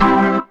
B3 CMAJ 1.wav